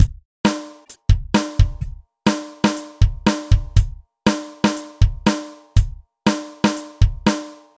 Drums
drums.mp3